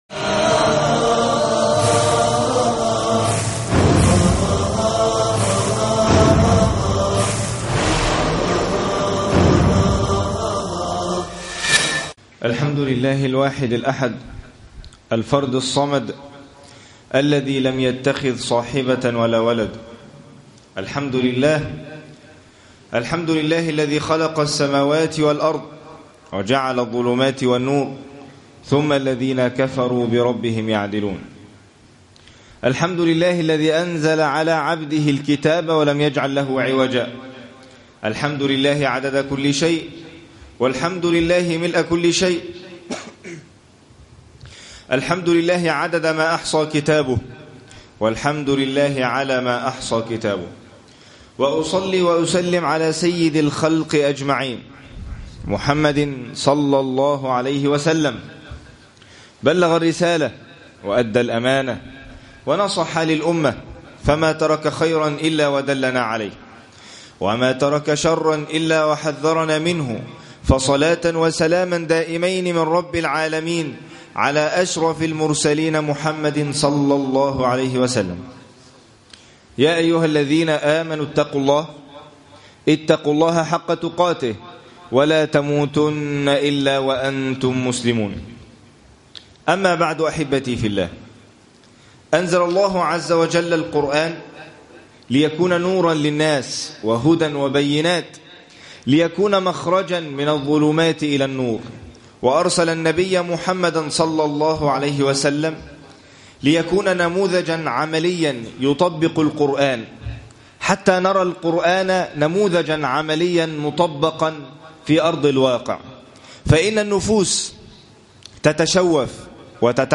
مسجد مصعب بن عمير بالمنصورة